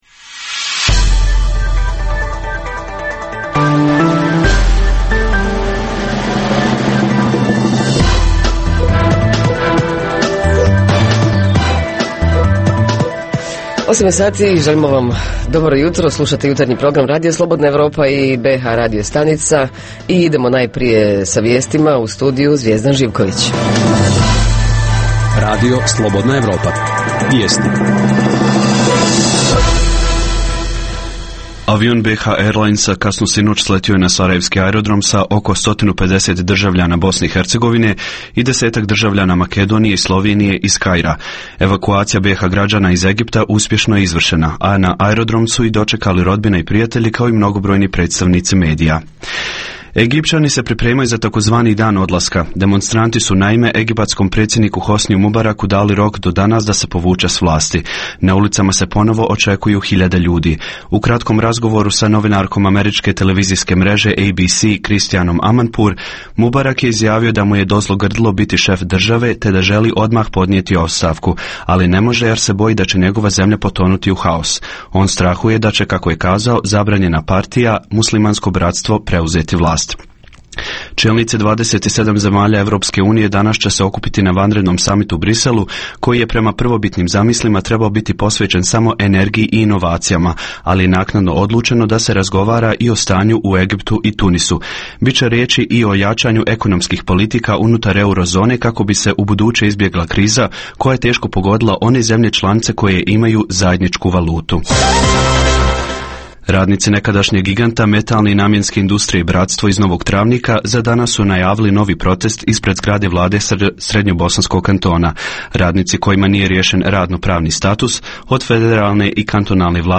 Najava najvažnijih kulturnih, sportskih ili nekih drugih događaja za naredni vikend u vašem gradu ili opštini. Reporteri iz cijele BiH javljaju o najaktuelnijim događajima u njihovim sredinama.
Redovni sadržaji jutarnjeg programa za BiH su i vijesti i muzika.